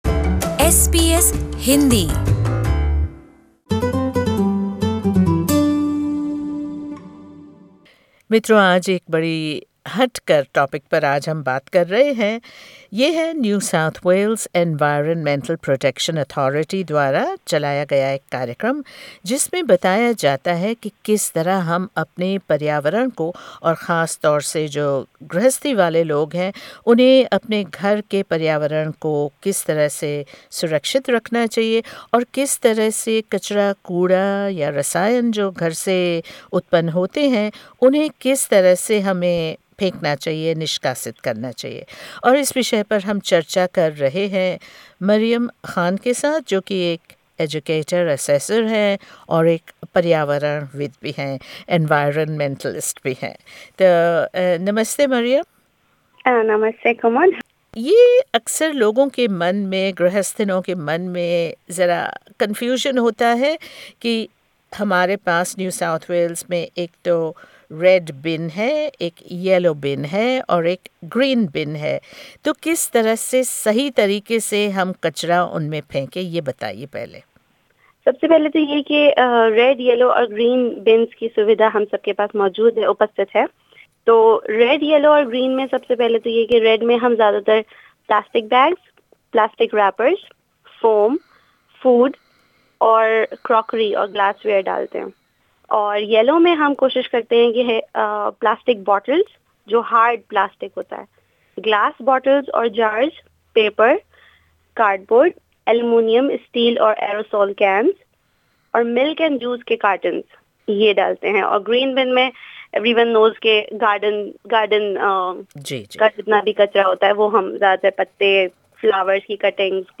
The NSW Environmental Protection Authority (EPA) wishes to raise awareness of regulatory requirements, delivering strong compliance and enforcement programs and driving improved waste and recycling practices with industry, business and the community. We spoke with educator/assessor and an environmentalist